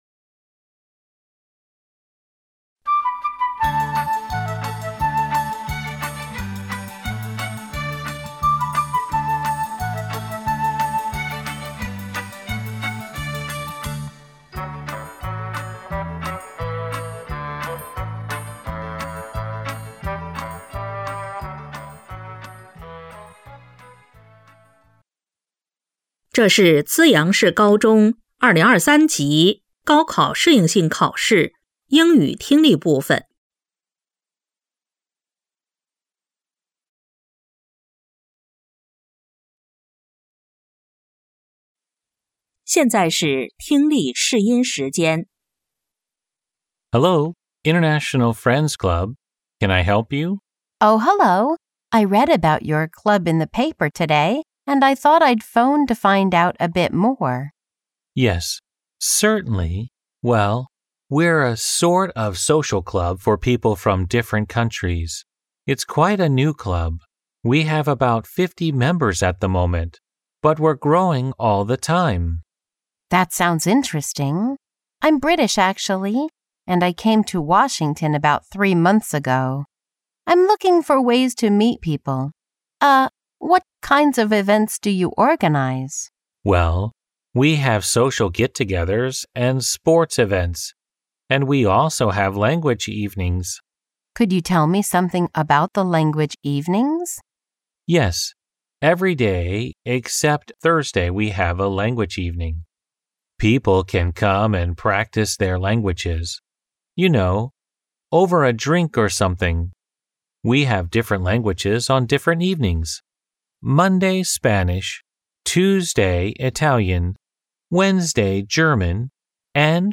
资阳市2026年高考适应性考试英语听力.mp3